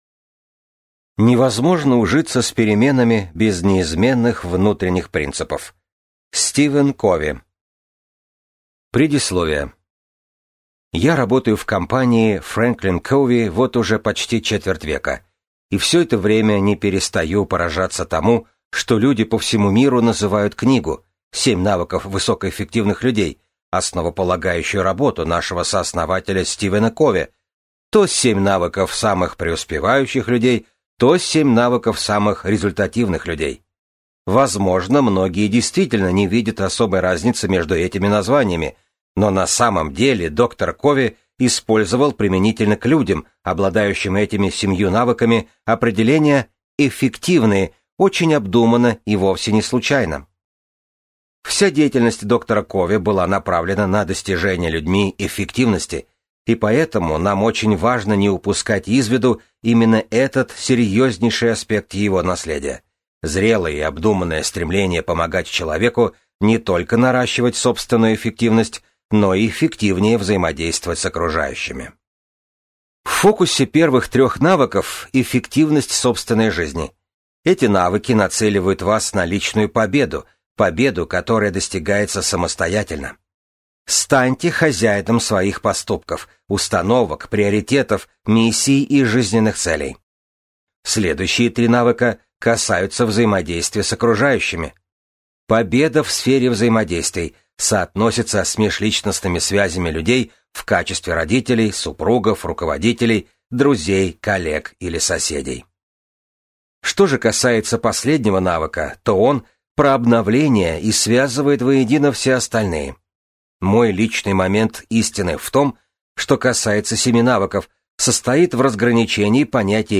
Аудиокнига Семь навыков на каждый день. Вечные истины в эпоху стремительных перемен | Библиотека аудиокниг